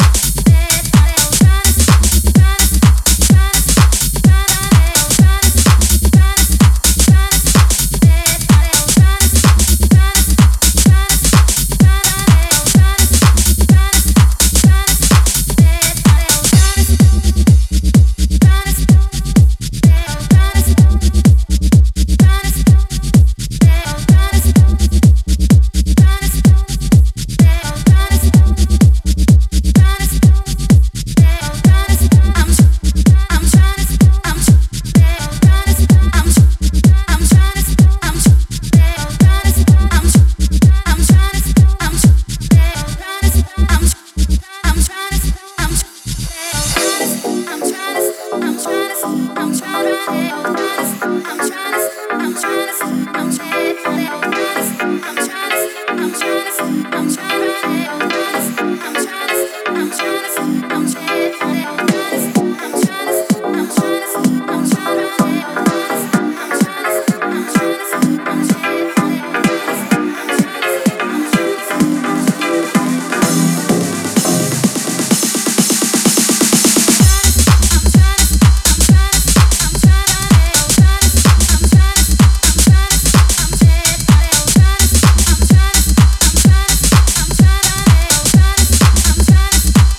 ジャンル(スタイル) DEEP HOUSE / TECH HOUSE / MINIMAL